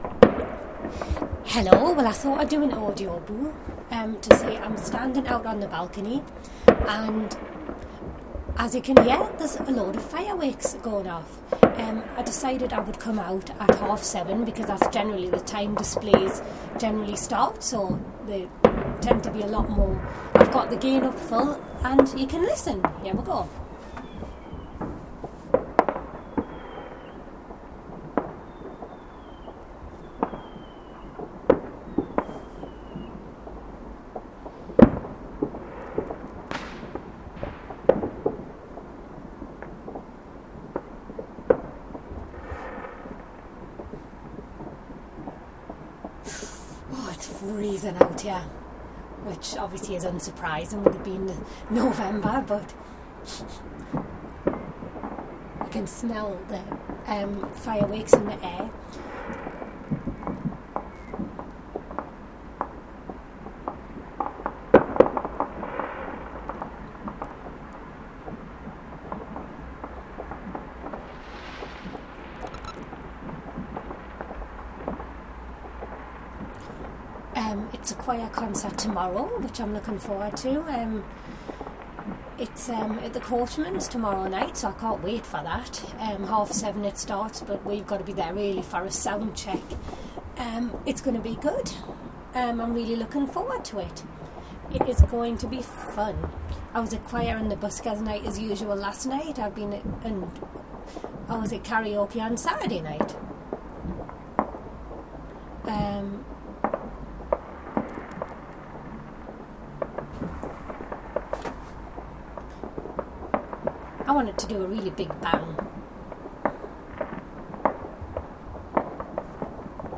fireworks!
I recorded these from my balcony.